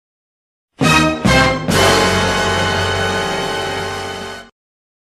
Meme sound